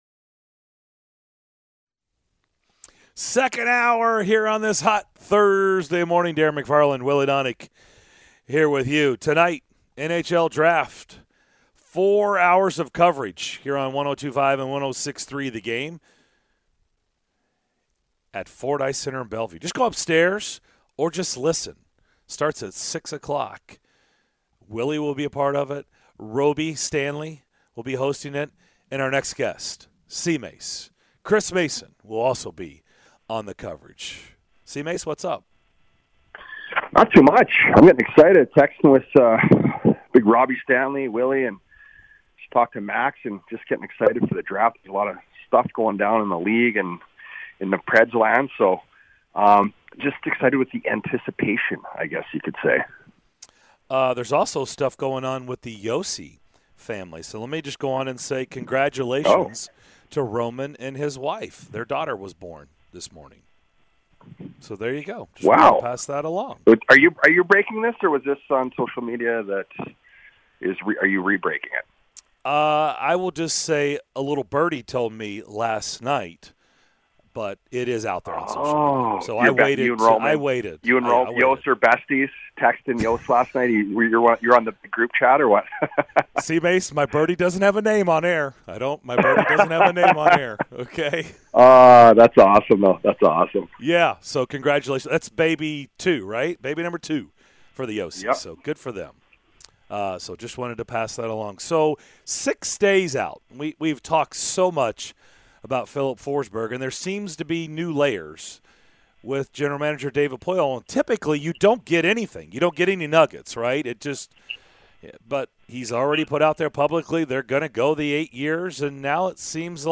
Chris Mason Full Interview (07-07-22)